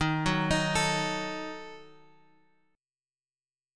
D#dim chord {x 6 4 x 4 5} chord
Dsharp-Diminished-Dsharp-x,6,4,x,4,5-8.m4a